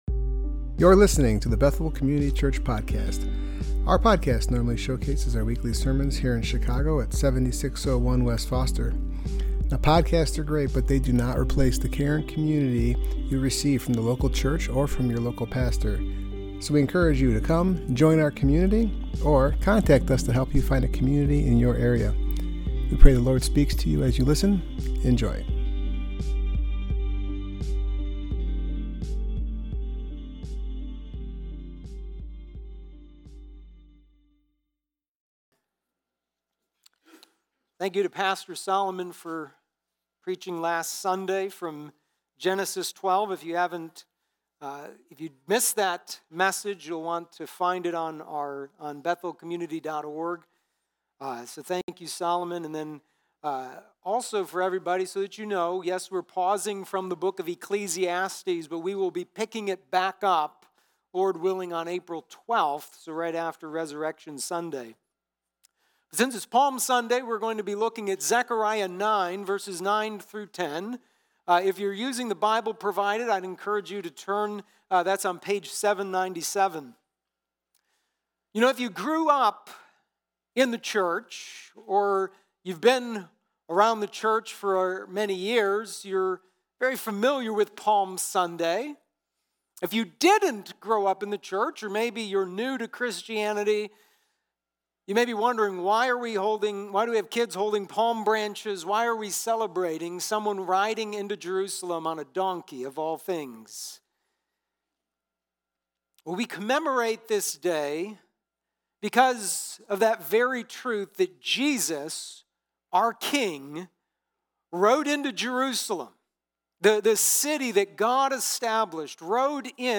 Passage: Zechariah 9:9–10 Service Type: Worship Gathering